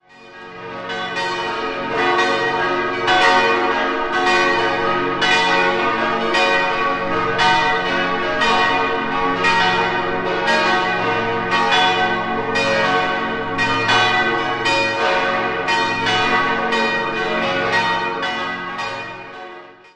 6-stimmiges Geläute: b°-des'-es'-ges'-b'-des'' Alle Glocken wurden 1906 von Rüetschi (Aarau) gegossen.